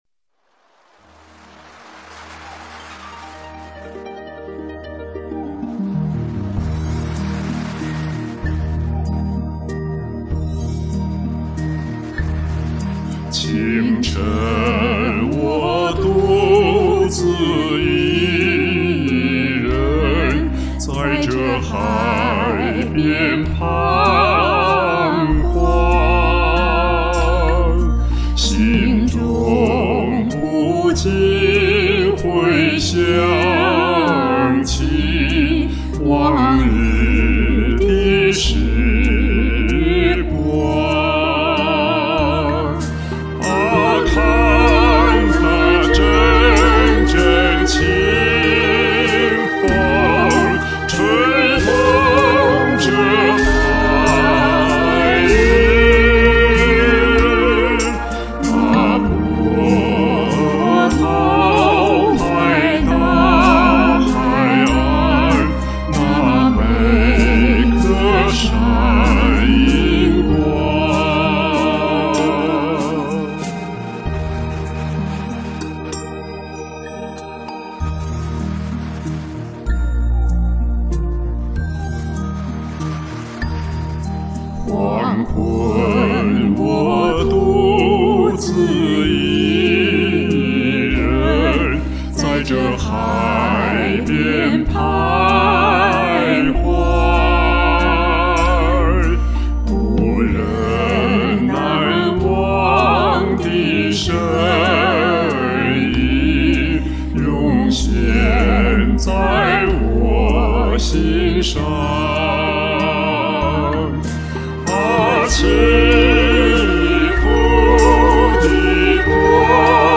三人版